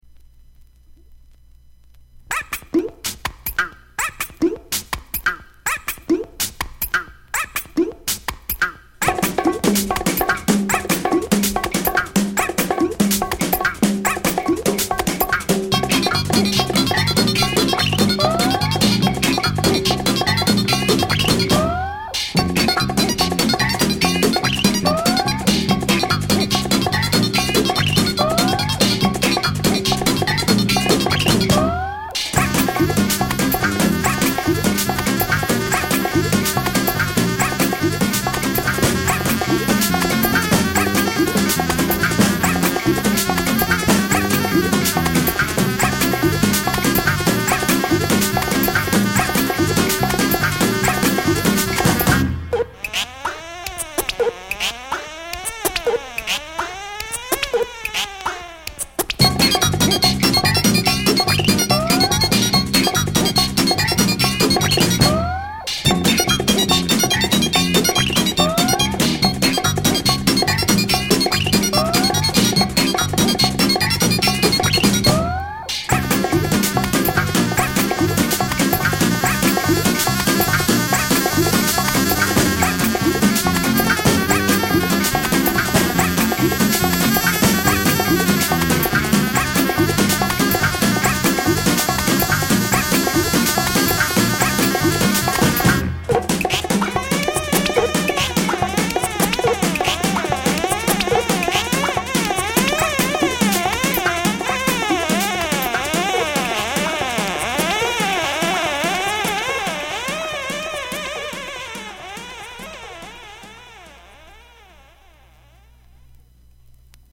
Electronic Pop Music of the Future